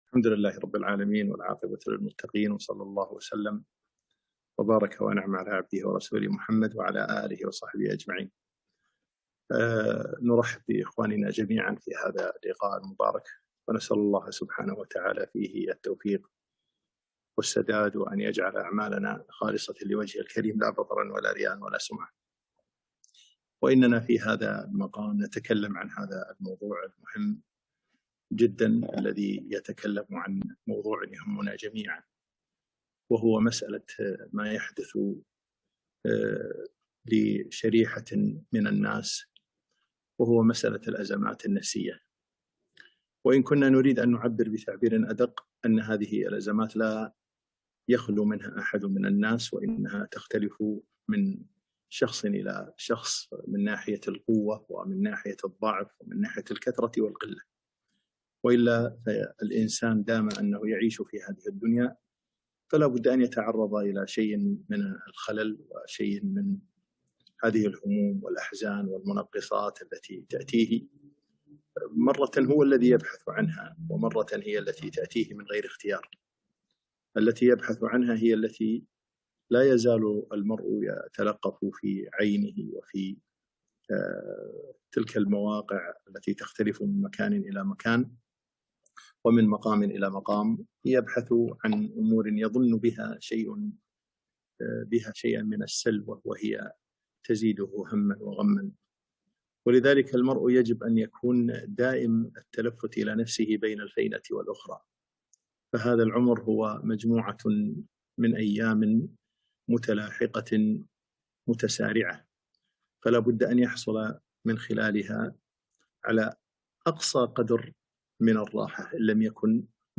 محاضرة - استثمر حياتك بإيجابية